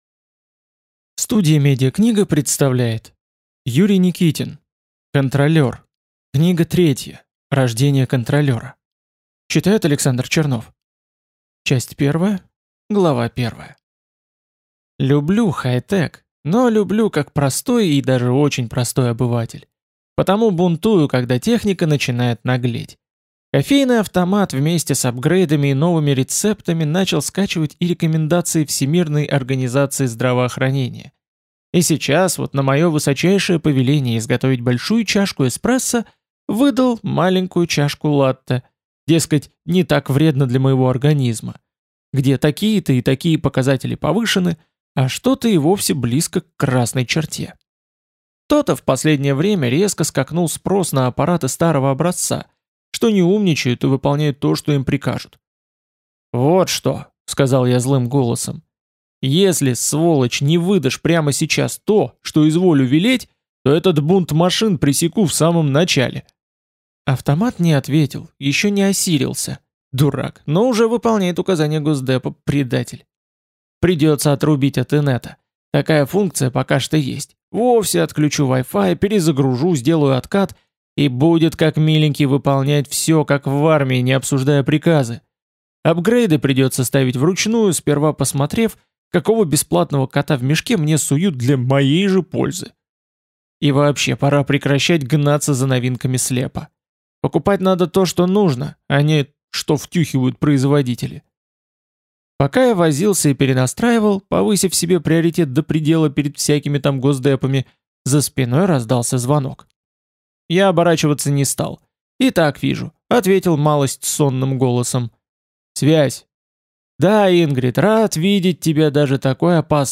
Аудиокнига Контролер. Рождение Контролера | Библиотека аудиокниг